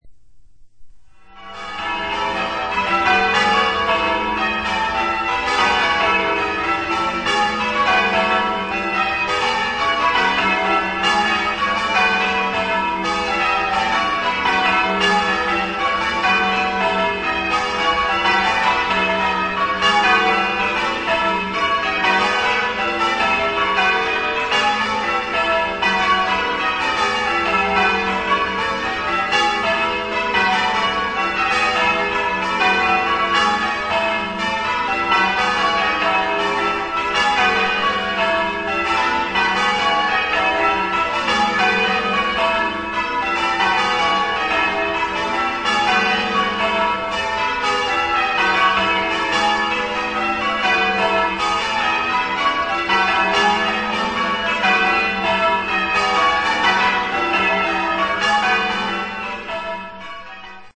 Listen to 1 minute of the final ¼ Peal on the old Bells,
Gt_Milton_final_qtr_peal_-_old_bells.mp3